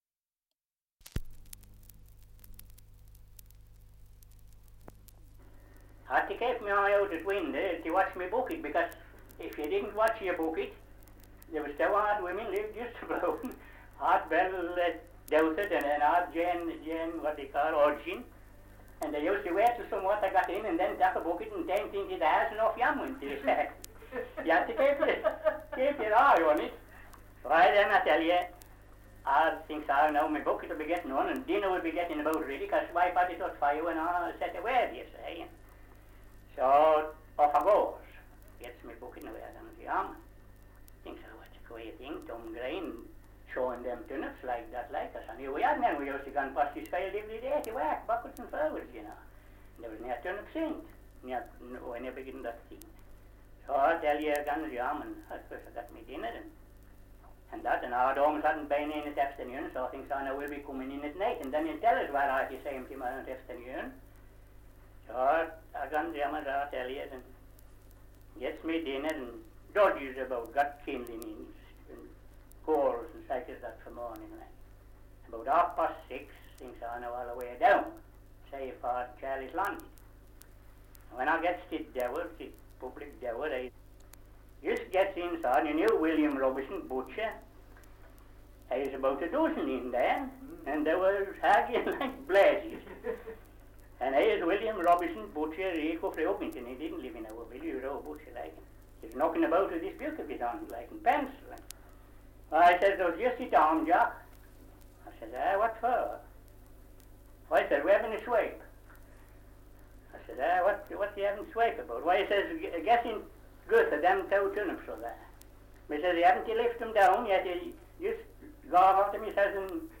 2 - Survey of English Dialects recording in Melsonby, Yorkshire
78 r.p.m., cellulose nitrate on aluminium